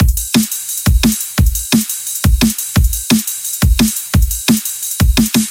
77拍
描述：77低音鼓节拍小鼓邦戈小鼓
Tag: 77 bpm Hip Hop Loops Drum Loops 2.10 MB wav Key : B